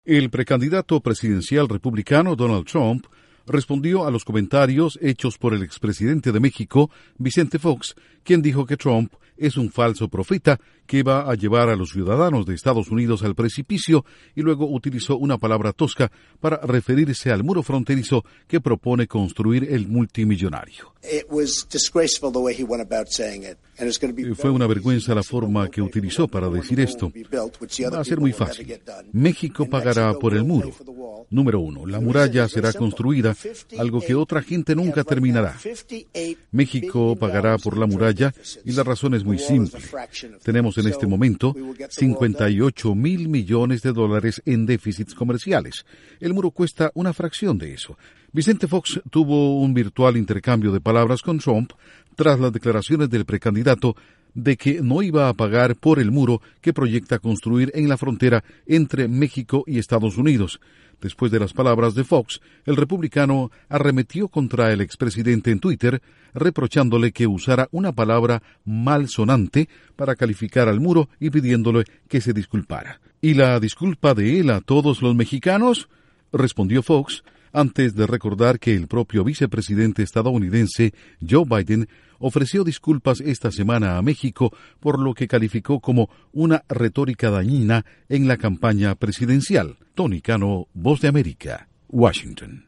Trump responde a expresidente de México que, entre otras cosas, lo calificó de “falso profeta”. Informa desde la Voz de América en Washington